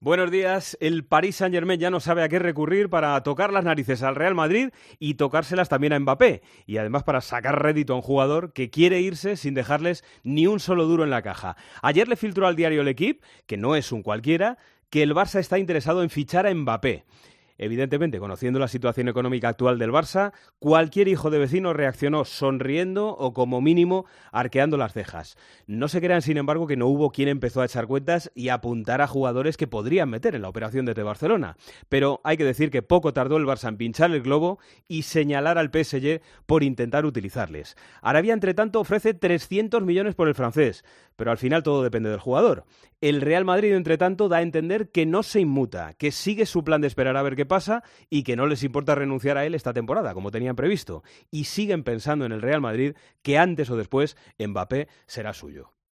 El presentador de 'El Partidazo de COPE' analiza la actualidad deportiva en 'Herrera en COPE'